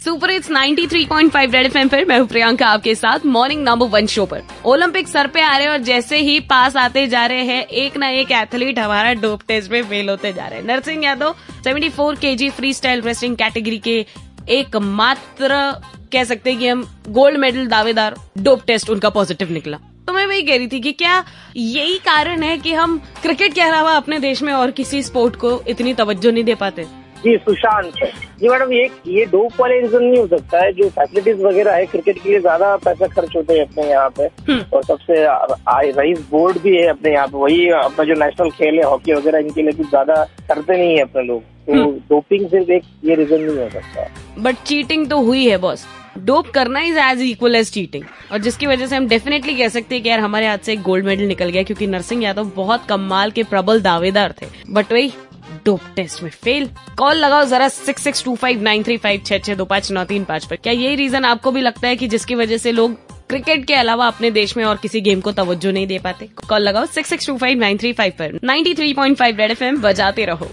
CALLER INTERACTION ON DOPING